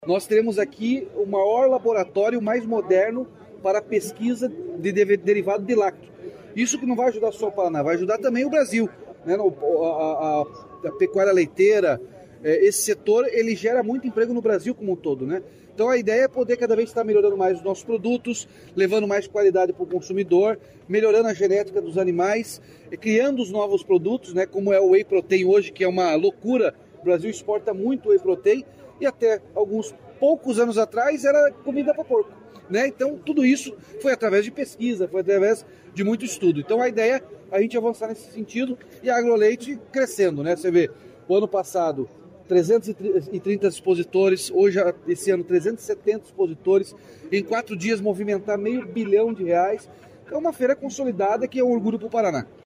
Sonora do governador Ratinho Junior sobre o anúncio laboratório de biotecnologia do leite e solução europeia para o agro em Castro